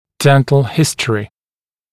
[‘dent(ə)l ‘hɪst(ə)rɪ]][‘дэнт(э)л ‘хист(э)ри]стоматологическая история болезни, стоматологический анамнез